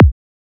edm-kick-50.wav